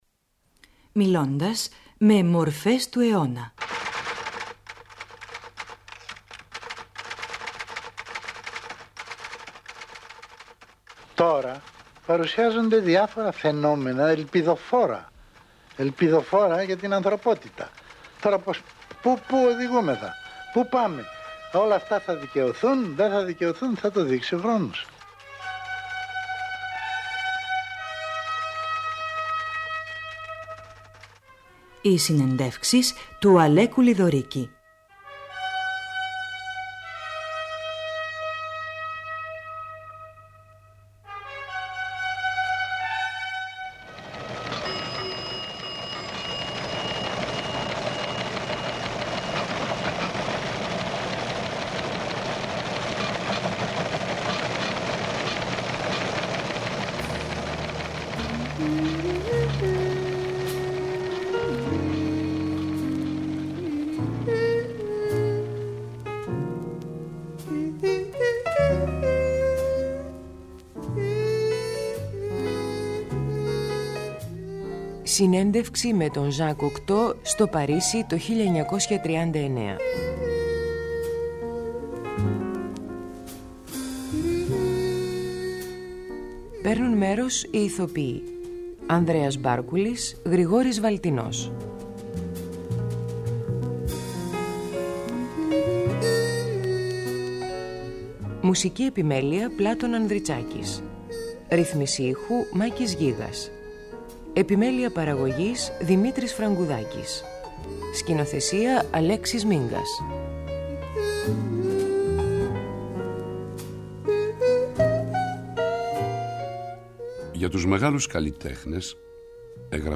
Δραματοποιημένη συνέντευξη του Έλληνα δημοσιογράφου με τον Ζαν Κοκτώ, τον επαναστάτη με το λογικό πνεύμα.
Στο ρόλο του Κοκτώ, ο Γρηγόρης Βαλτινός Στο ρόλο του Αλέκου Λιδωρίκη , ο Ανδρέας Μπάρκουλης .